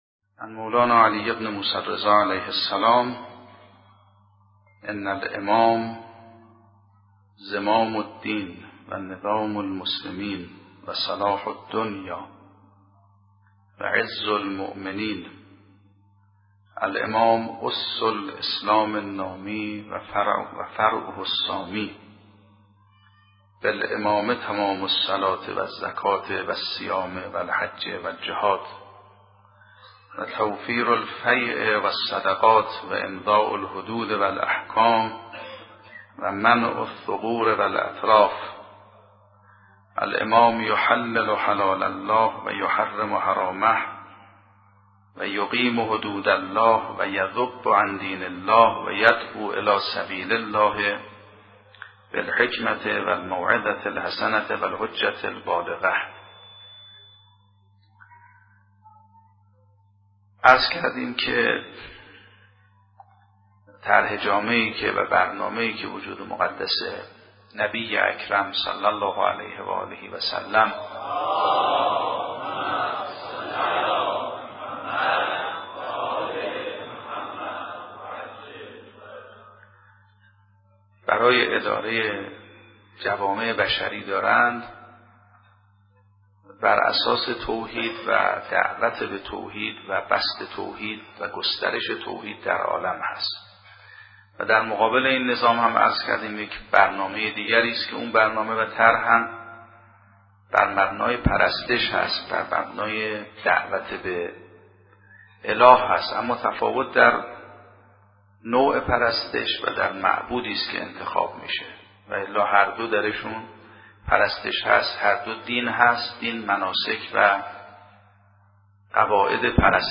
سخنرانی آیت‌الله میرباقری با موضوع حرکت جوامع ذیل برنامه نبوی - جلسات 4 تا 6